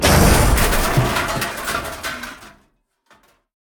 crash2.ogg